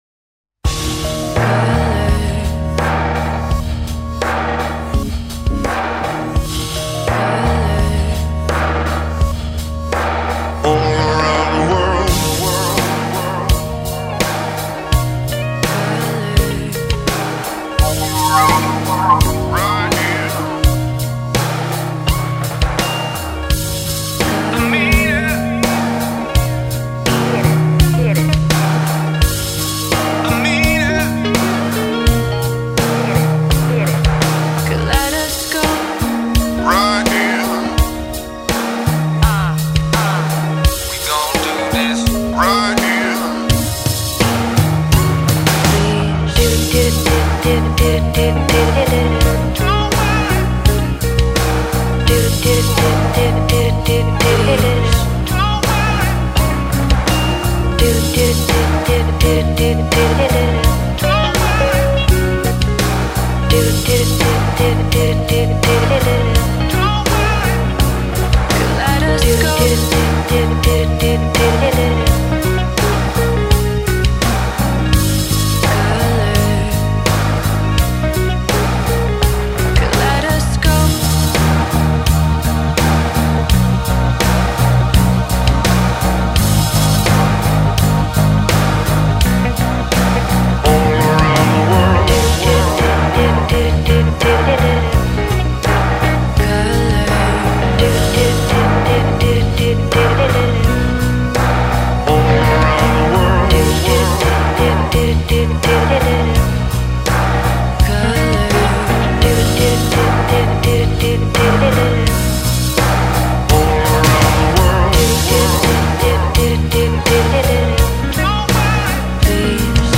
Largo [0-10] amour - guitare electrique - - -